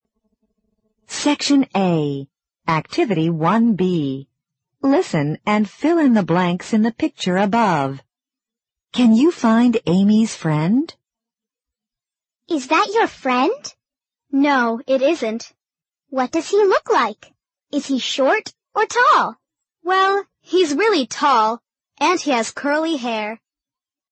【人教版初一英语七年级下册课文朗读听力mp3】Unit 9